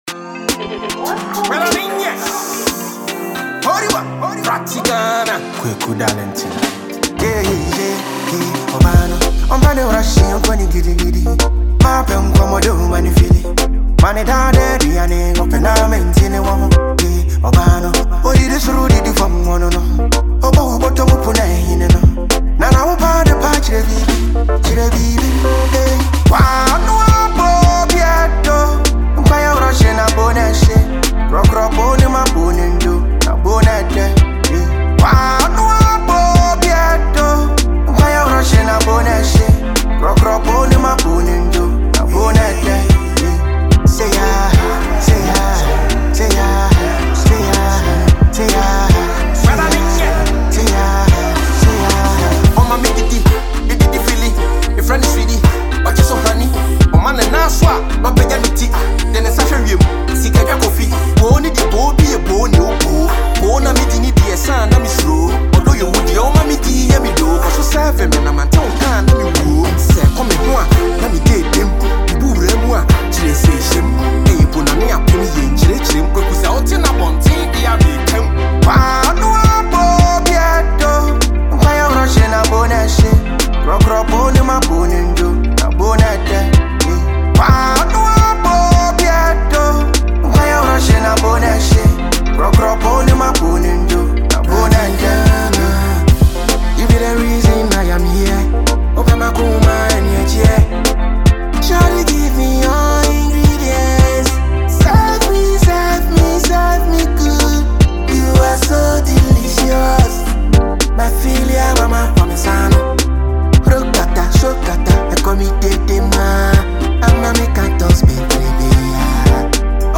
The high-energy Ghanaian rapper and lyricist
hard-hitting gem